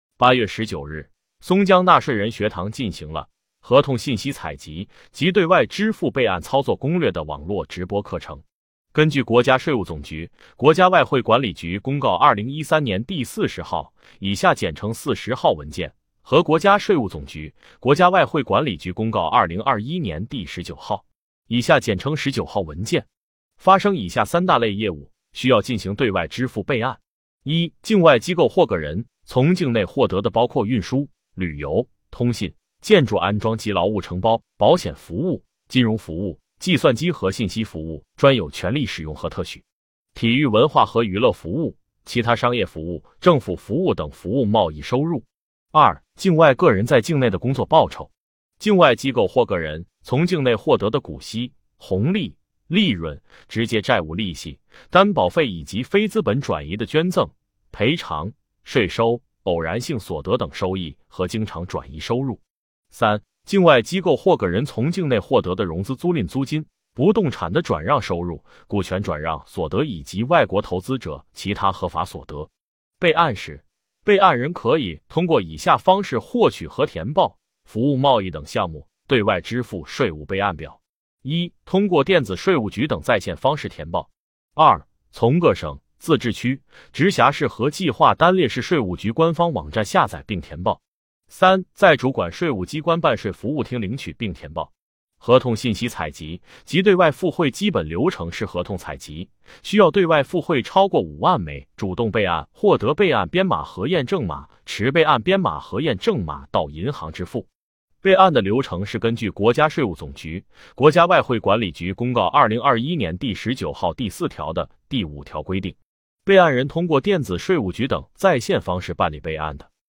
目前，松江区税务局通过网络直播的形式开展纳税人学堂。